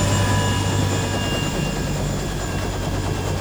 propellersstop.wav